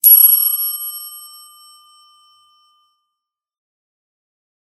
お仏壇に置いてある「チーン」と ならすものの音。
正式名称は「 鈴（りん） 」 と言います。